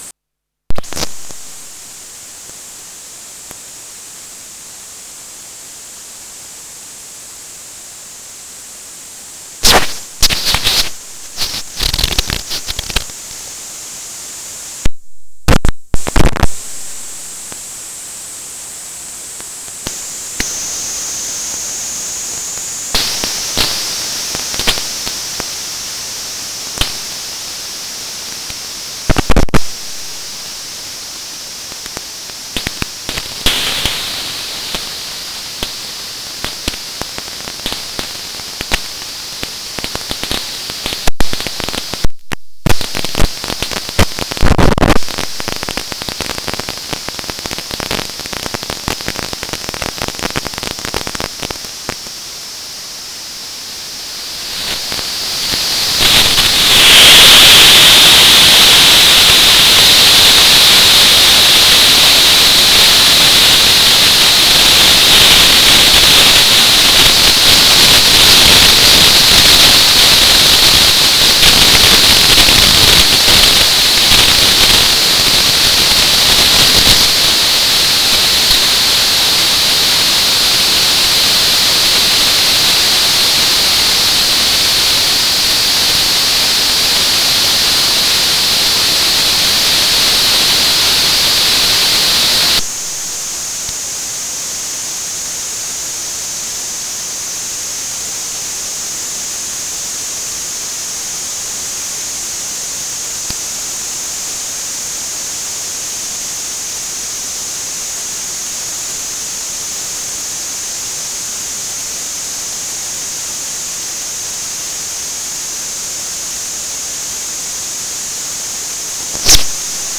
This below is an ultrasound recording in my home and also the same in my garden using an ultrasound microphone.
This is the recording and trace when I did the “covering the mic aperture with your finger”Â test.
My Ultrasound microphone is plugged into the line-in recording jack and recorded using an ultrasound scanner program.
recording-ultrasound-closing-aperture-of-mic.wav